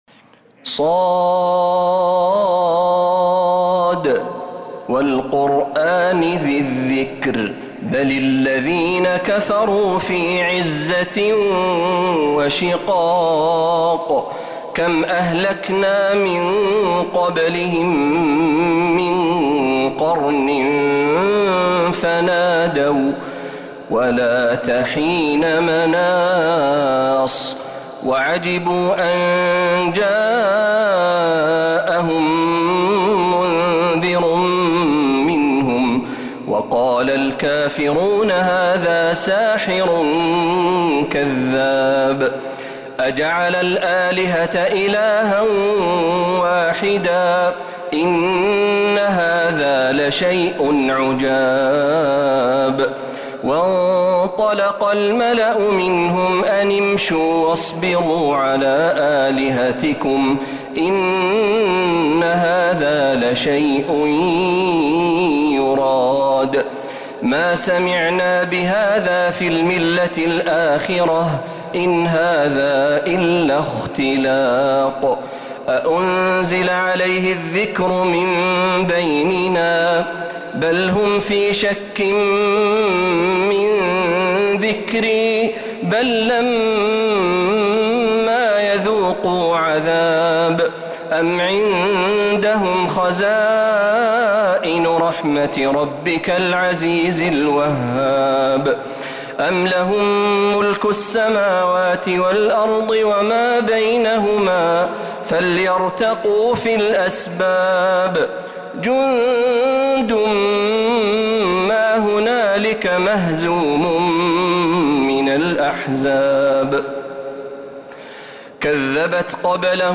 سورة ص كاملة من الحرم النبوي